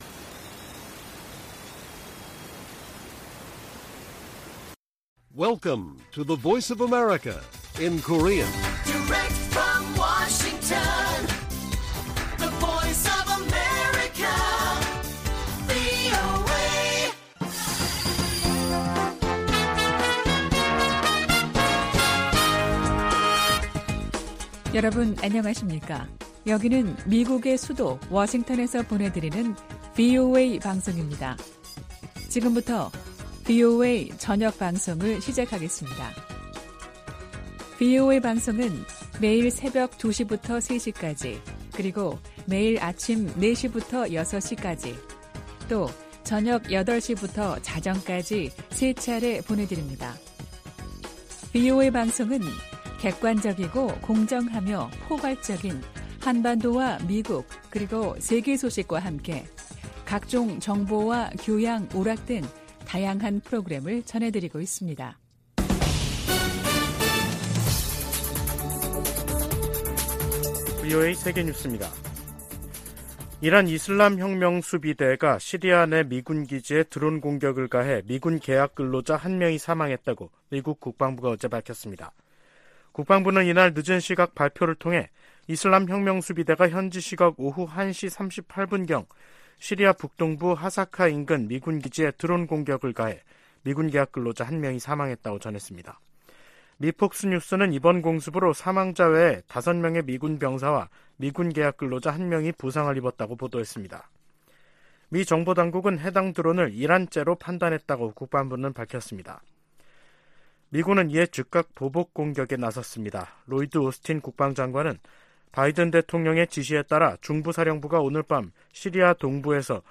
VOA 한국어 간판 뉴스 프로그램 '뉴스 투데이', 2023년 3월 24일 1부 방송입니다. 북한이 '핵 무인 수중 공격정' 수중 폭발시험을 진행했다고 대외관영 매체들이 보도했습니다. 로이드 오스틴 미 국방장관은 북한을 지속적인 위협으로 규정하며 인도태평양 지역에서 방위태세를 강화하고 훈련 범위와 규모도 확대하고 있다고 밝혔습니다. 윤석열 한국 대통령은 '서해 수호의 날' 기념사에서 북한의 무모한 도발에는 대가를 치르게 하겠다고 강조했습니다.